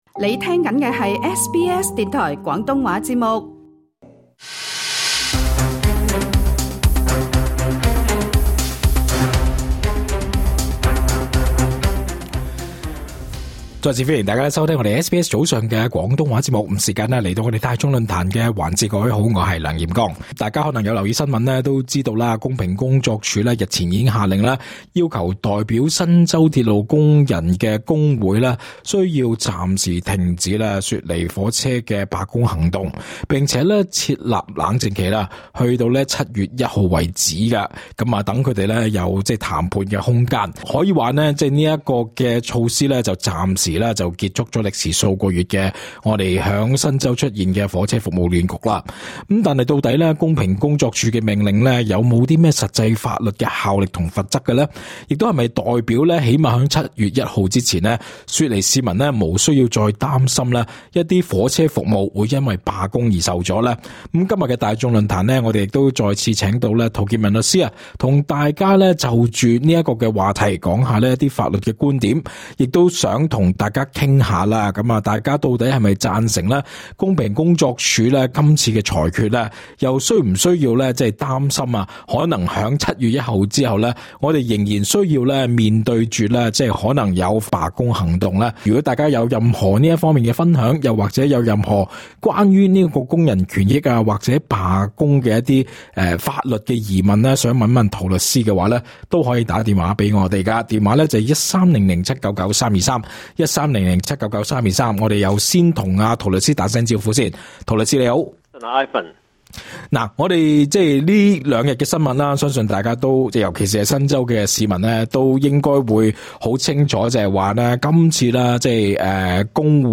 足本訪問： LISTEN TO 【雪梨火車罷工危機未除】亂局九月前仍有機會重現？